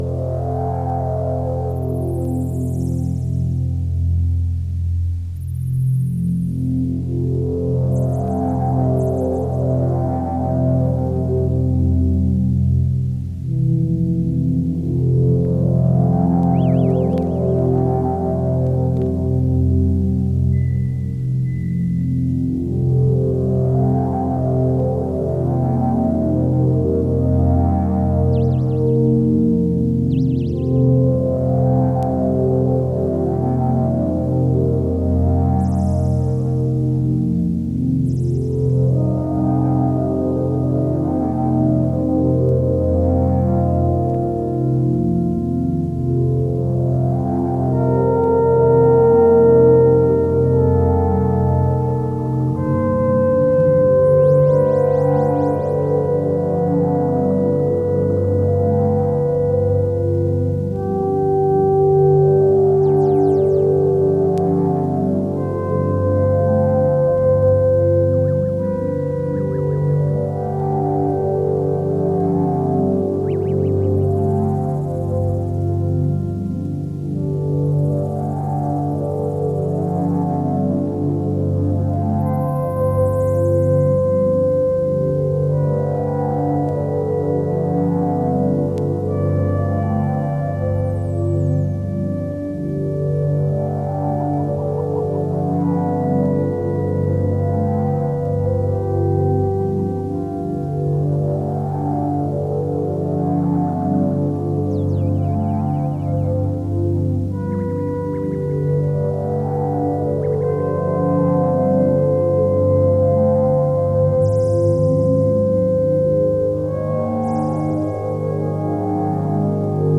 the studio album
Electronix Ambient Experimental